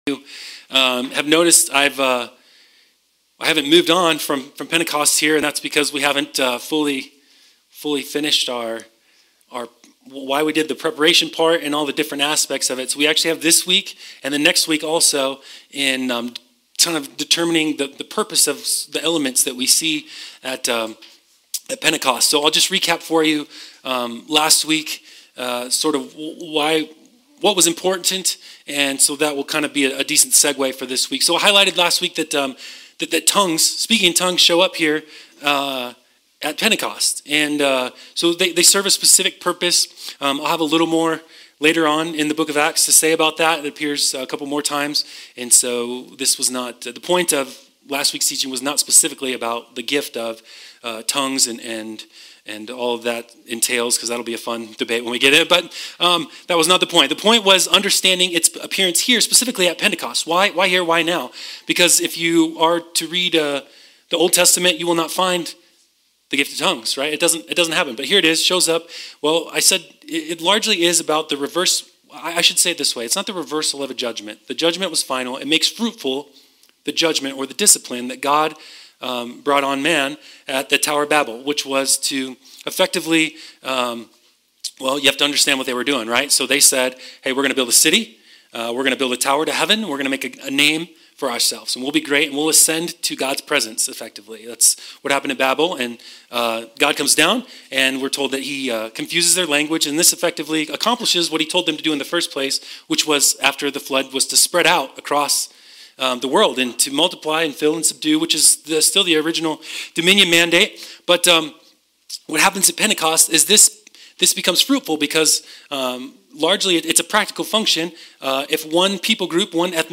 Sermons - LUMA BIBLE CHURCH